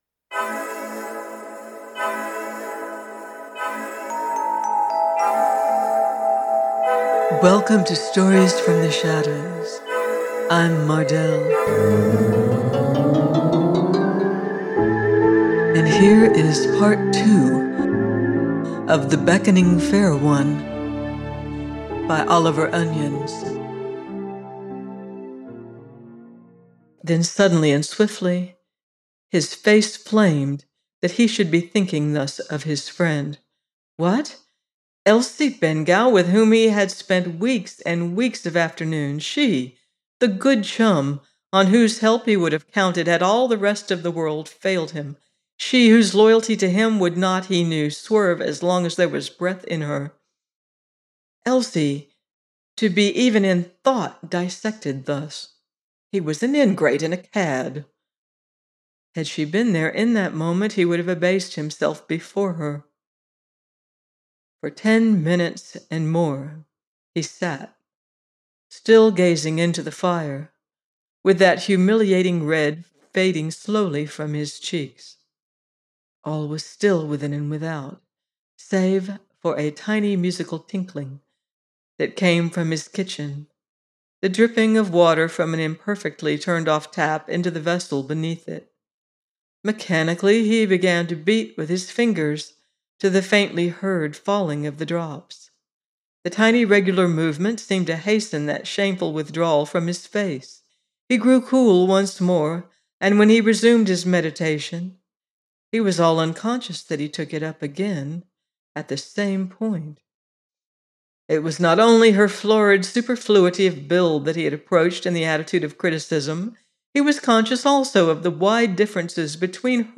The Beckoning Fair One – Oliver Onions - audiobook